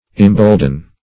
imbolden - definition of imbolden - synonyms, pronunciation, spelling from Free Dictionary Search Result for " imbolden" : The Collaborative International Dictionary of English v.0.48: Imbolden \Im*bold"en\, v. t. See Embolden .